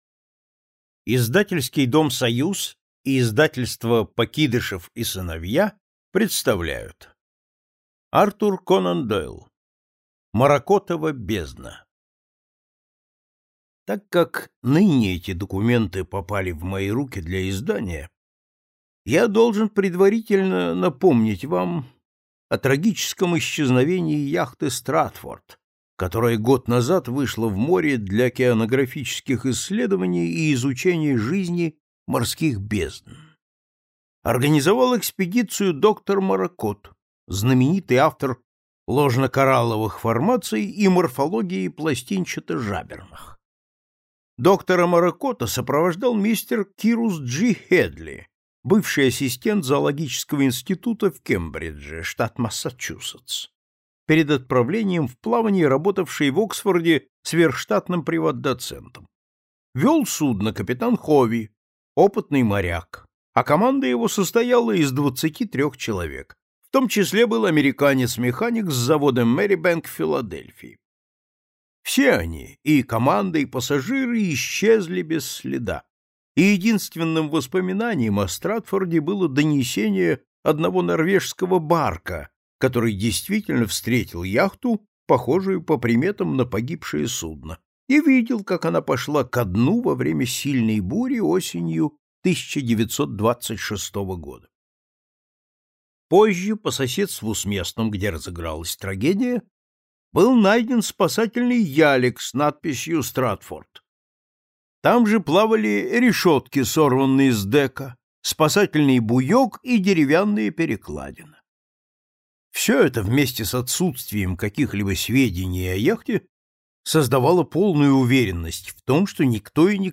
Аудиокнига Маракотова бездна